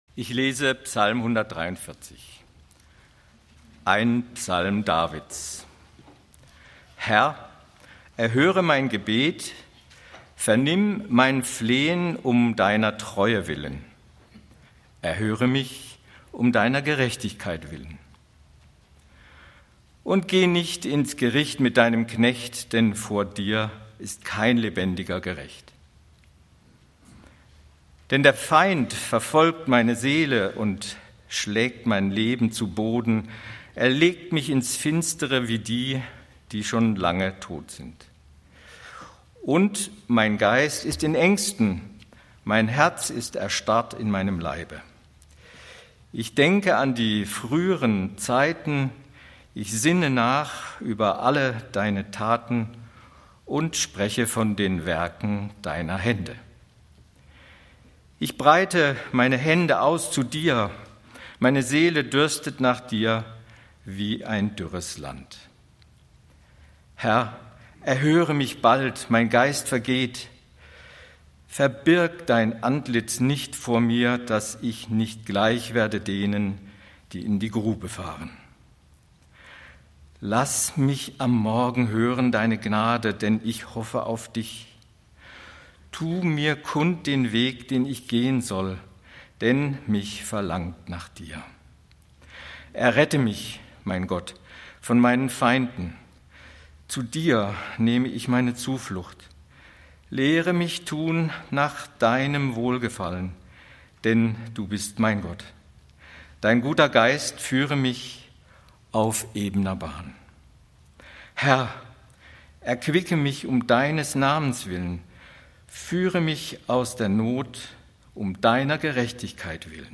Psalmsonntag - "Tu mir kund den Weg" (Ps. 143) - Gottesdienst